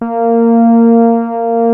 P.5 A#4 4.wav